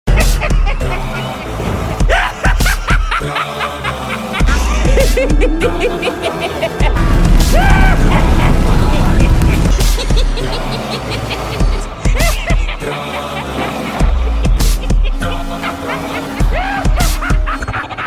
A Male Ghost In The Sound Effects Free Download